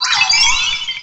pokeemerald / sound / direct_sound_samples / cries / azelf.aif